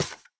Monster_Spawner_step3_JE1_BE1.wav